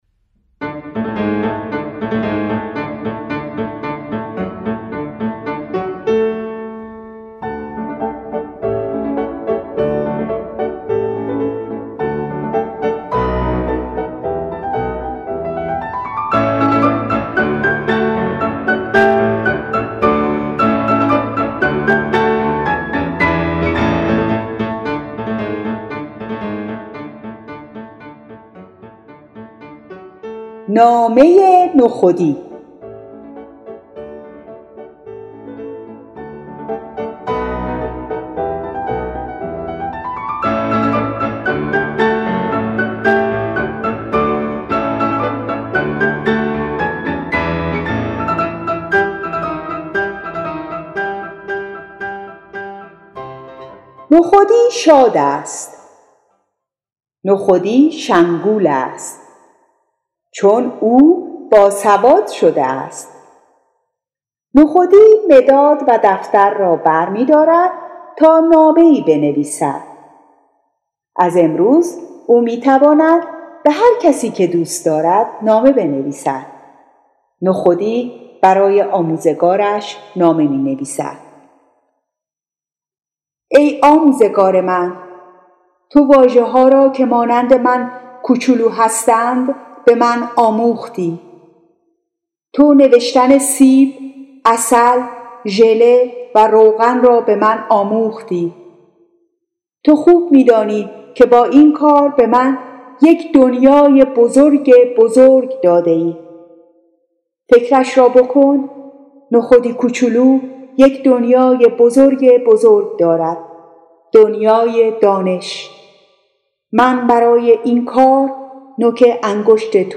داستانک‌های صوتی فارسی‌آموز نخودی 1